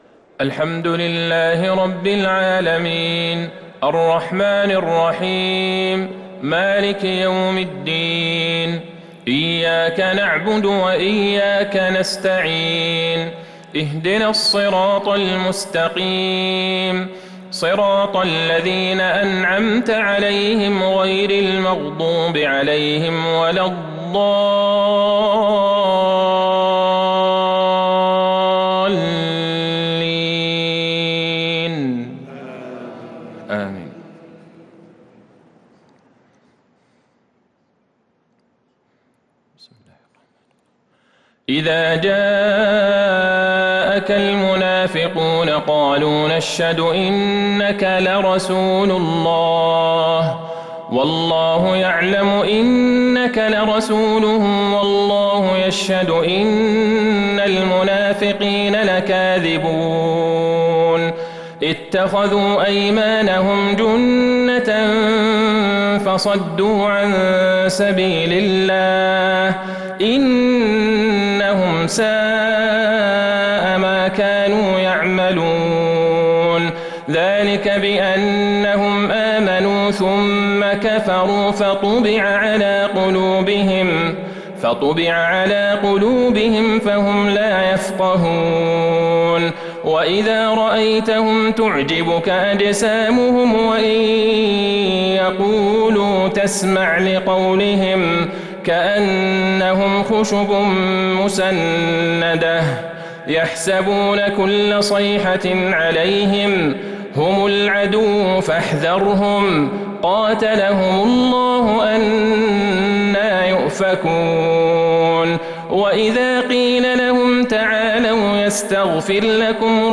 عشاء السبت ٢٣ ذو القعدة ١٤٤٢هـ | سورة المنافقون | Isha prayer from Surah Al-monafeqon 3-7-2021 > 1442 🕌 > الفروض - تلاوات الحرمين